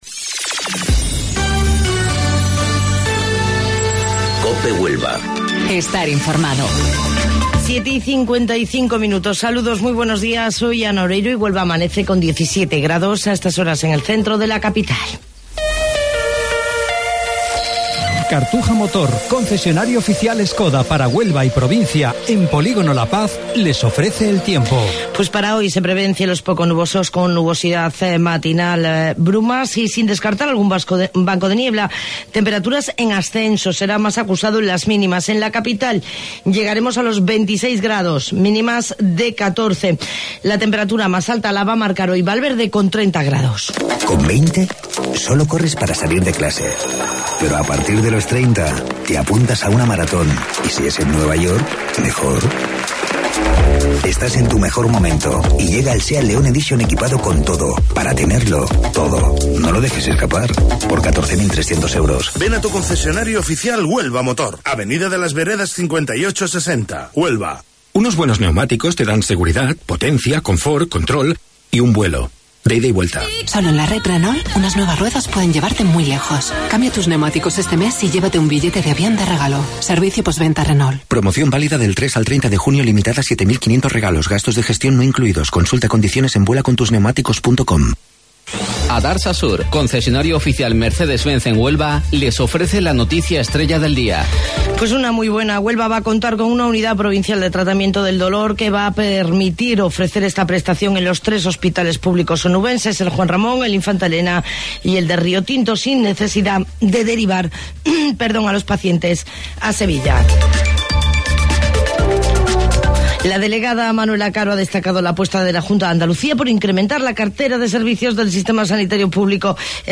AUDIO: Informativo Local 07:55 del 19 de Junio